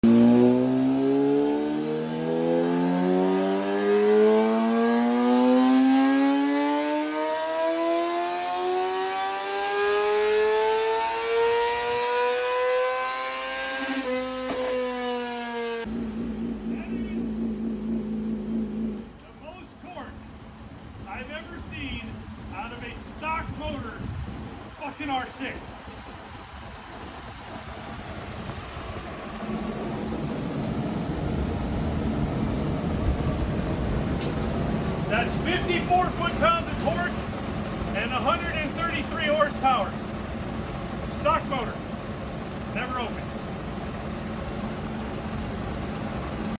R6 Drinking Some Margarita Mix Sound Effects Free Download